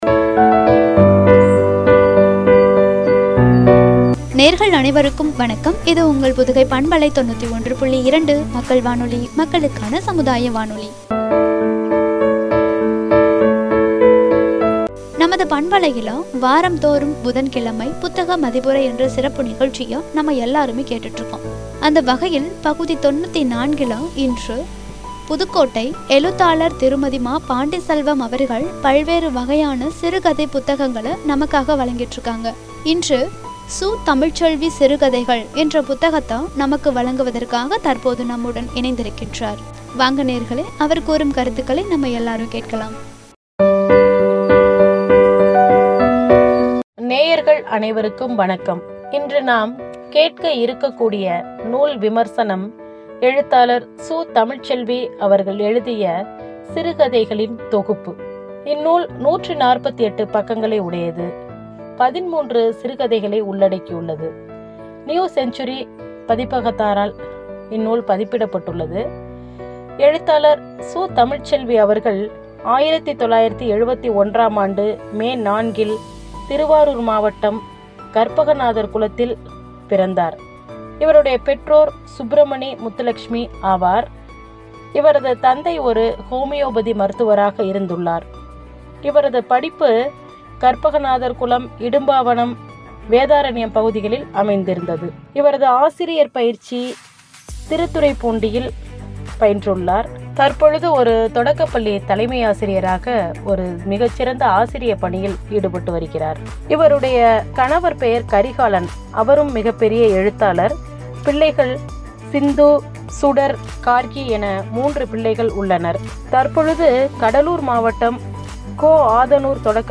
புத்தக மதிப்புரை -சு.தமிழ்ச்செல்வி சிறுகதைகள்